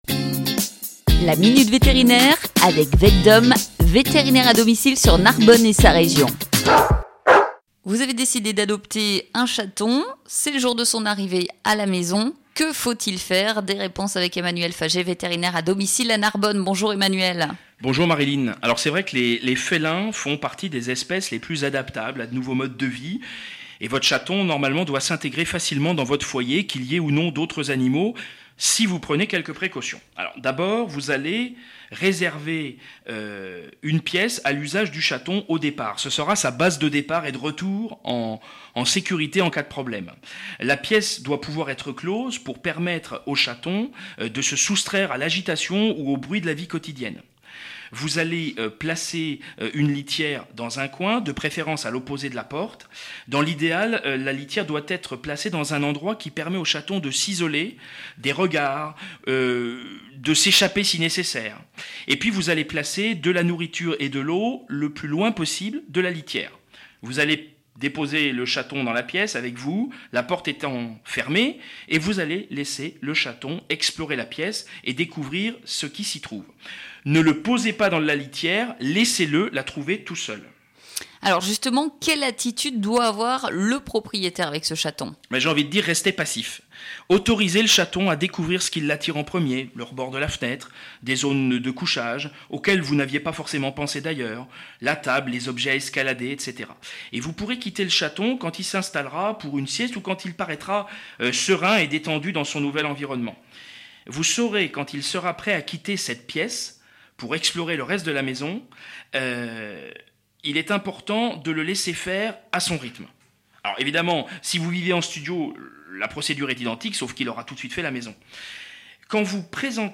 La Minute vétérinaire sur GrandSud FM avec VETDOM